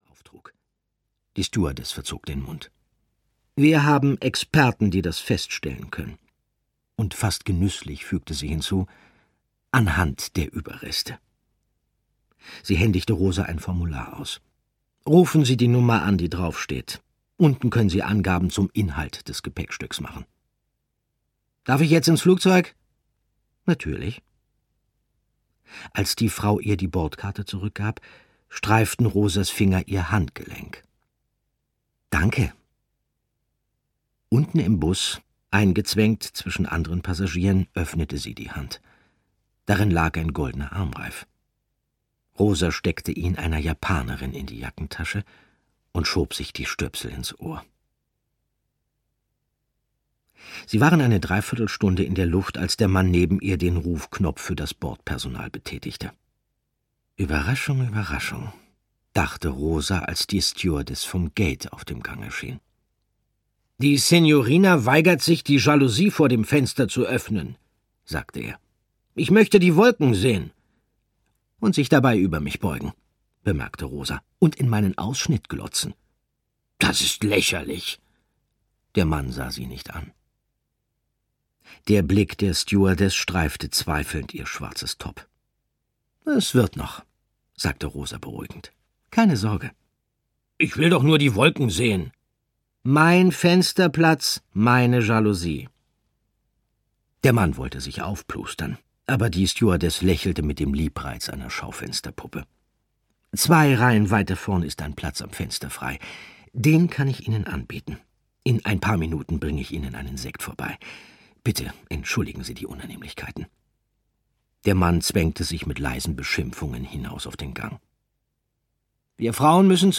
Arkadien-Reihe 1: Arkadien erwacht - Kai Meyer - Hörbuch